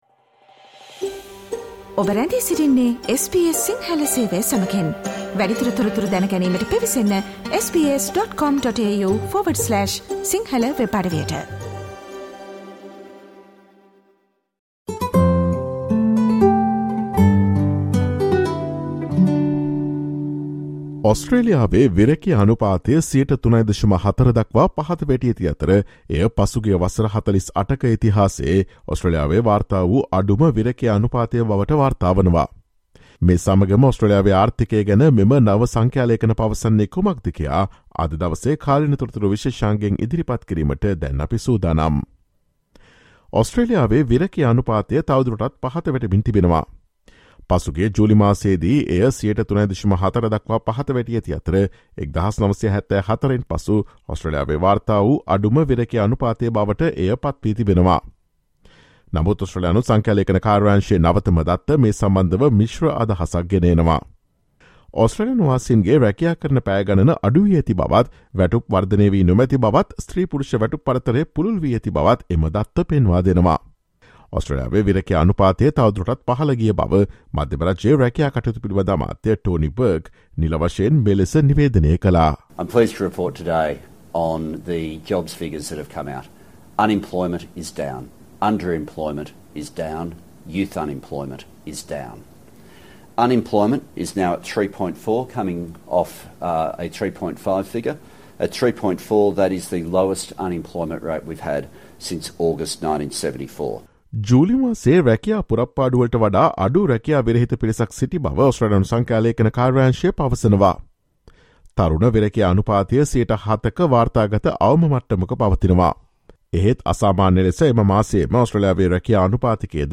Australia's unemployment rate has fallen to 3.4 % , its lowest level in 48 years. So what exactly do the figures tell us about Australia's economy? Listen to the SBS Sinhala Radio's current affairs feature broadcast on Friday 19 August.